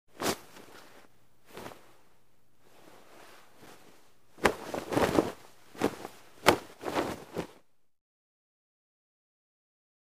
MovingAroundSheets PE382001
Moving Around In Sheets And Blanket